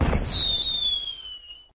firework_whistle_02.ogg